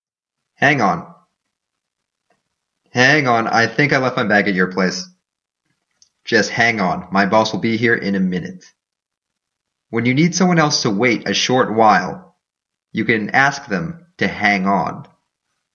英語ネイティブによる発音は下記のリンクをクリックしてください。
hangon.mp3